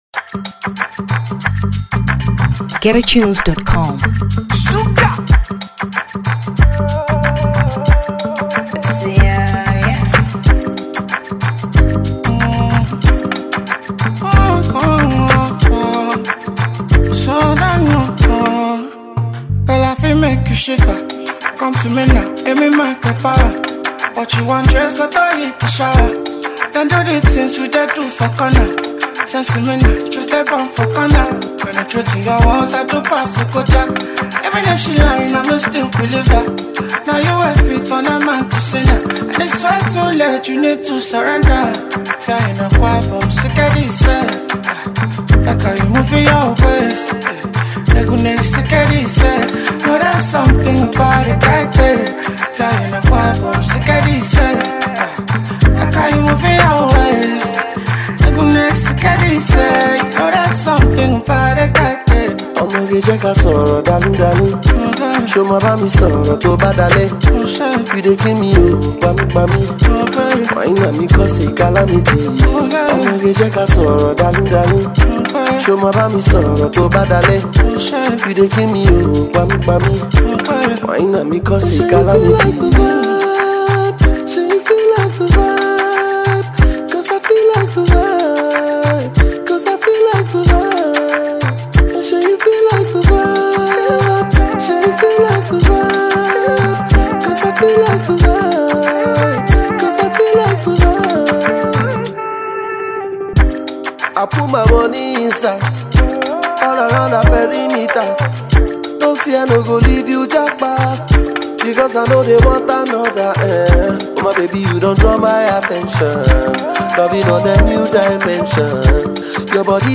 Afrobeat 2023 Nigeria